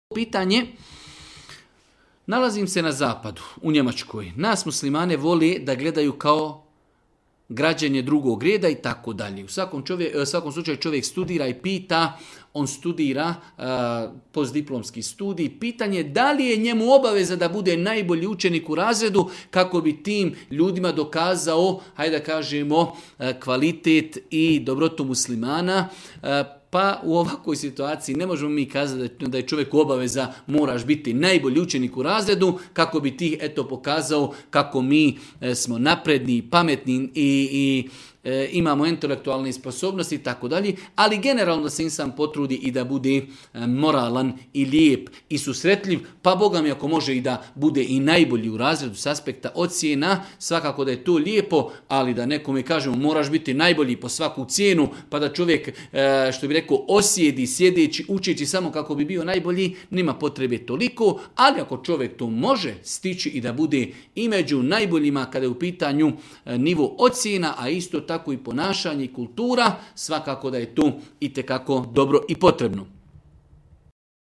Audio isječak odgovora Tvoj web preglednik ne podrzava ovaj fajl, koristi google chrome.